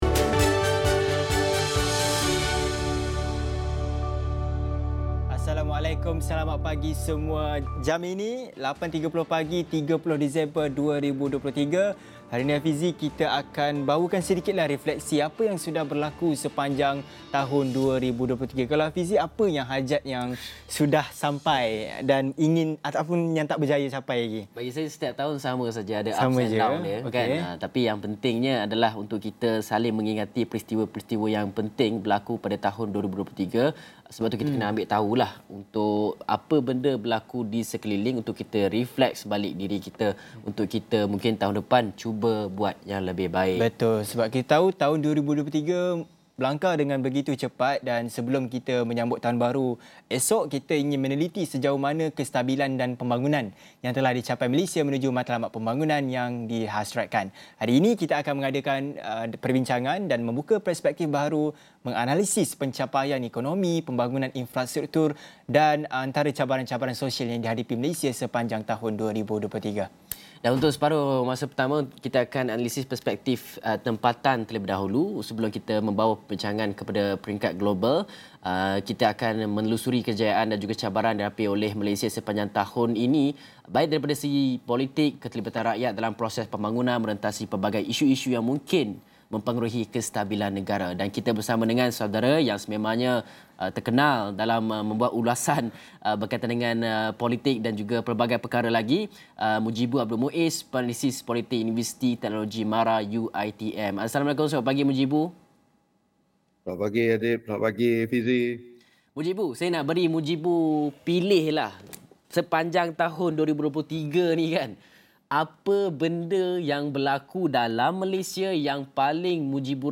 Ikuti diskusi dalam program AWANI Pagi bersama pakar yang akan membuka perspektif diskusi tempatan dan posisi negara di mata dunia.